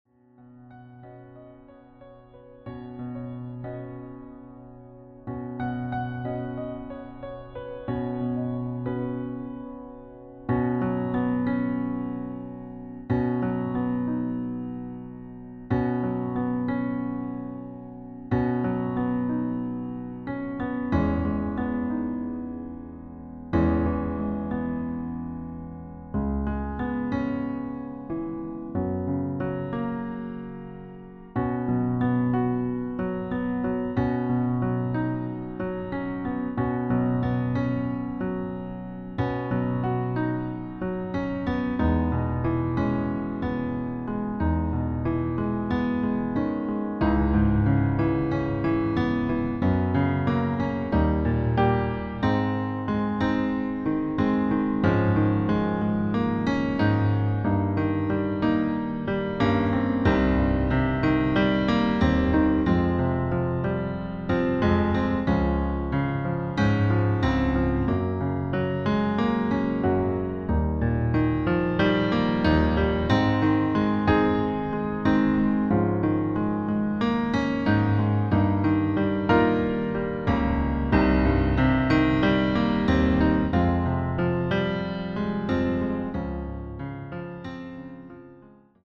Demo in H Dur:
• Das Instrumental beinhaltet NICHT die Leadstimme
Flügeleinspielung